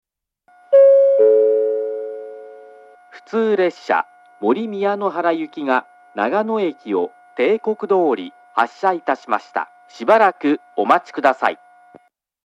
１番線長野駅発車案内放送 普通森宮野原行の放送です。
列車が長野駅を発車すると流れます。